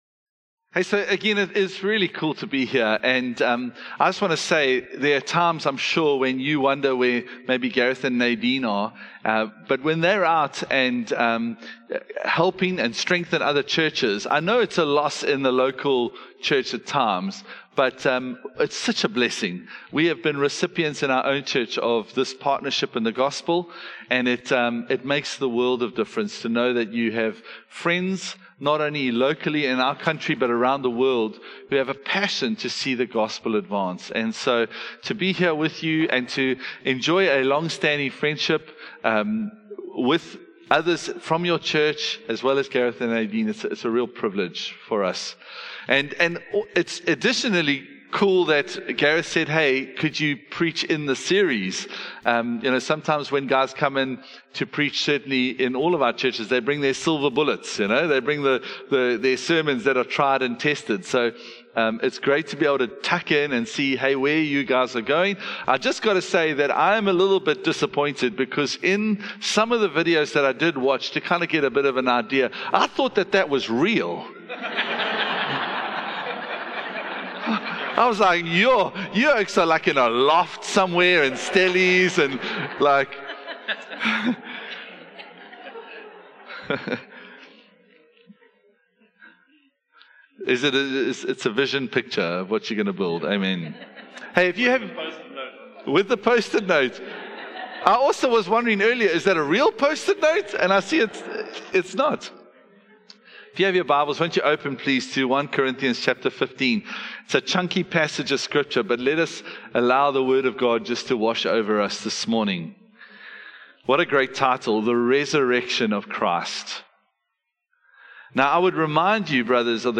The resurrection of Jesus Christ is the unshakable foundation of our faith, not an optional belief. This truth from 1 Corinthians 15 is explored in a sermon that dives into the absolute core that underpins everything we believe.